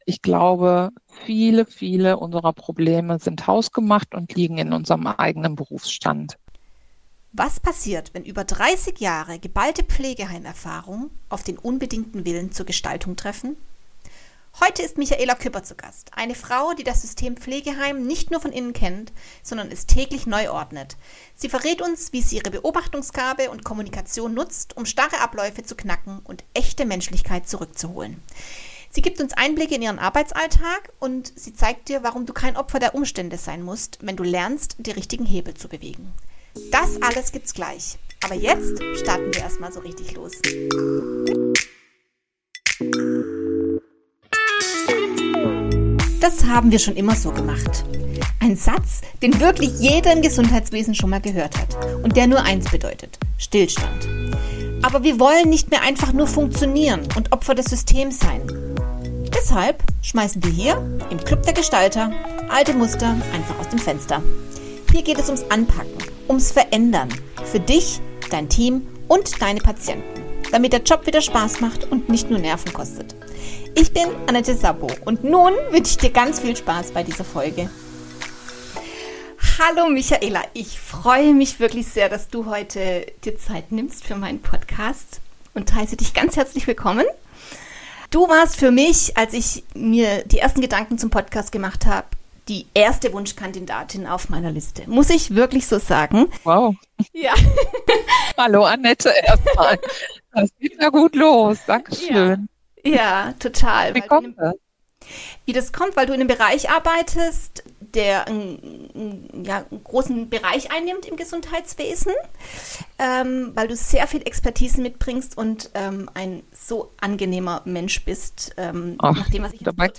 Ein tiefgehendes Gespräch über die Rückeroberung der fachlichen Souveränität und die Kunst der echten Begegnung.